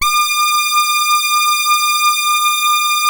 86-SAWRESWET.wav